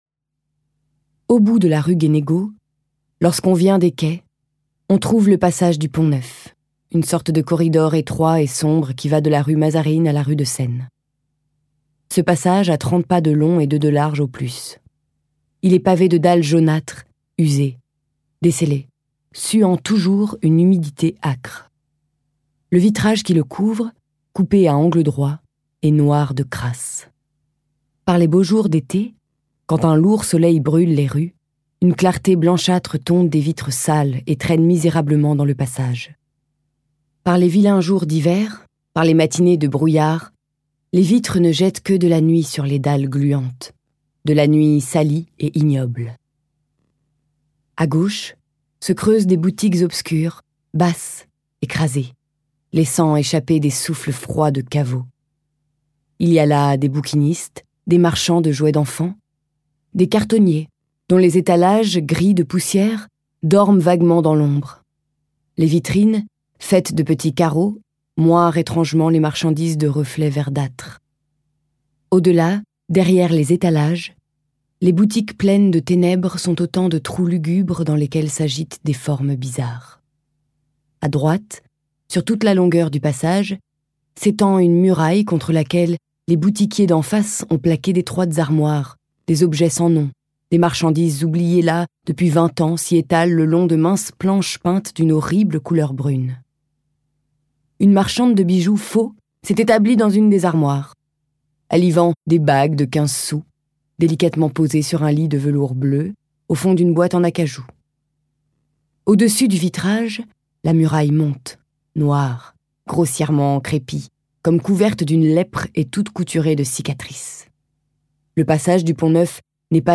Lu par Rebecca Marder